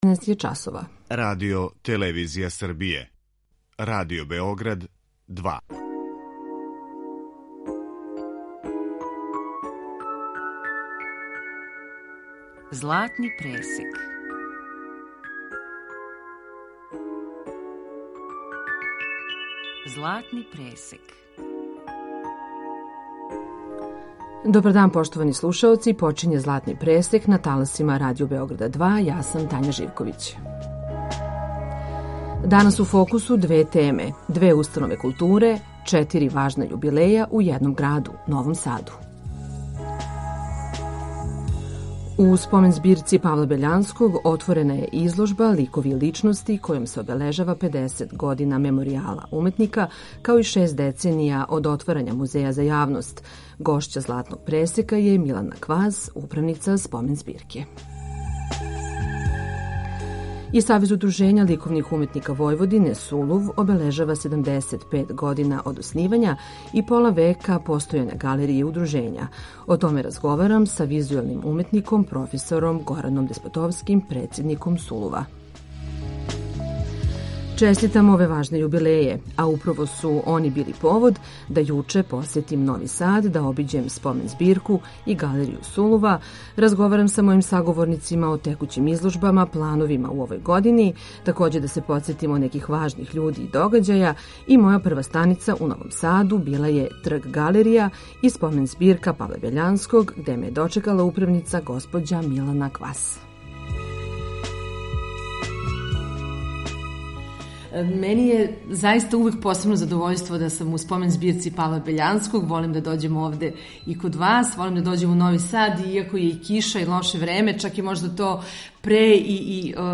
Аудио подкаст Радио Београд 2